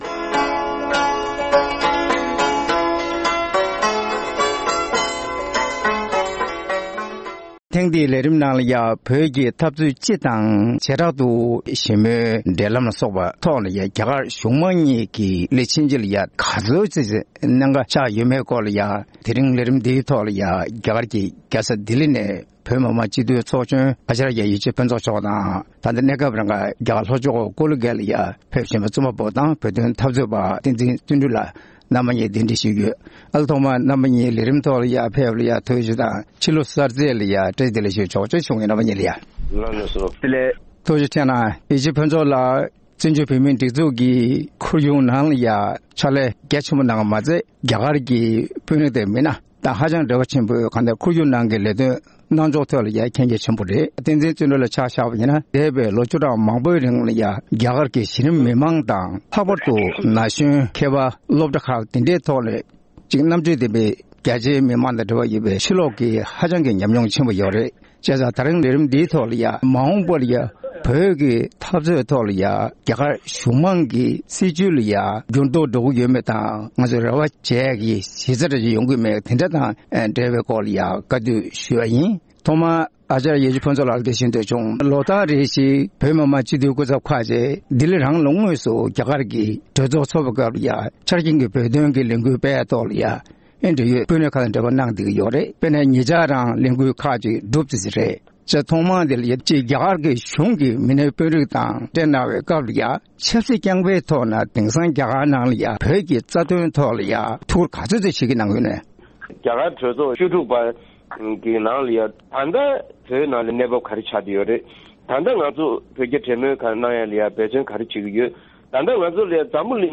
བོད་ཀྱི་འཐབ་རྩོད་སྤྱི་དང་བྱེ་བྲག་ཏུ་བོད་རྒྱ་ཞི་མོལ་དང་འབྲེལ་བའི་ཐད། རྒྱ་གར་གཞུང་དམངས་གཉིས་ཀྱི་གནད་འགག་དང་འབྲེལ་ཡོད་སྐོར་གླེང་མོལ།